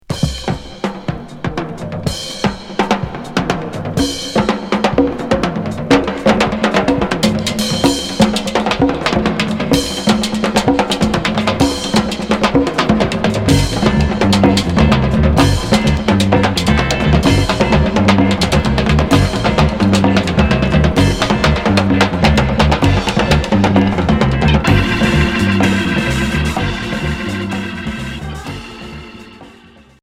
Groove progressif Santanesque Unique 45t